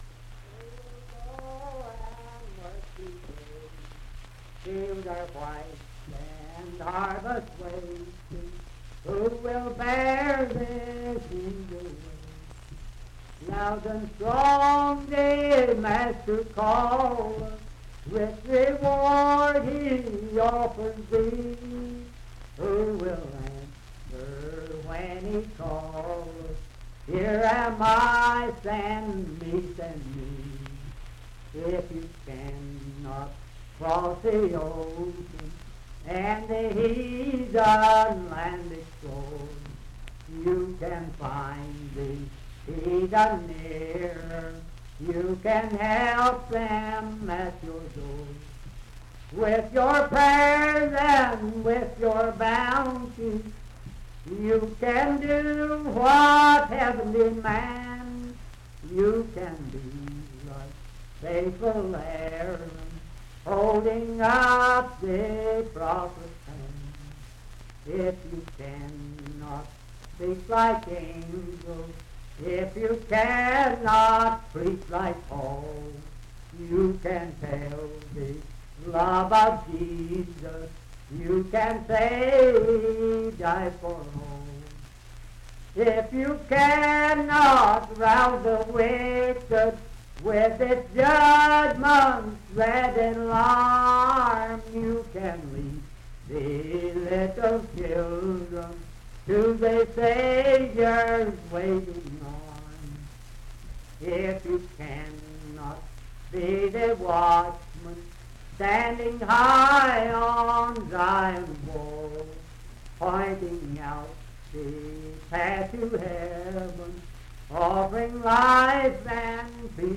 Unaccompanied vocal music and folktales
Hymns and Spiritual Music
Voice (sung)
Wood County (W. Va.), Parkersburg (W. Va.)